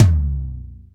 TOM RLTOM0FL.wav